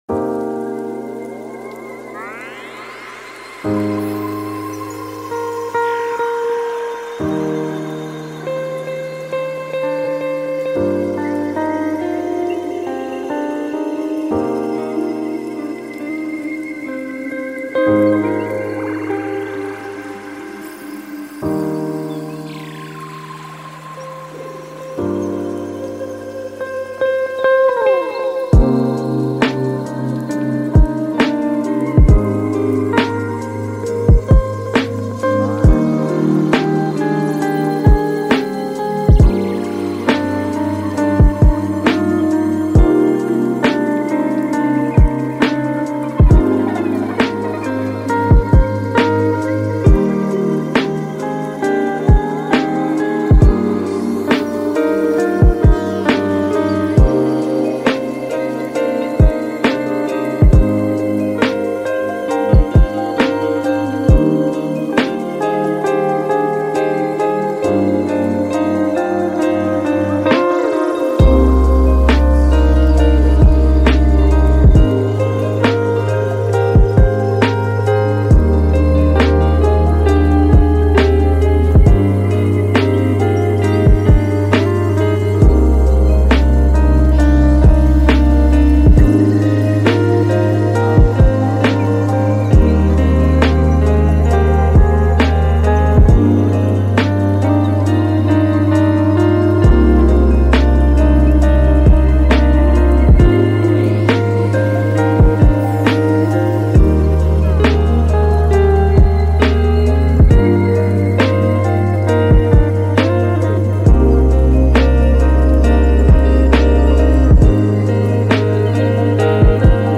Mozart Profond : Lecture Apaisée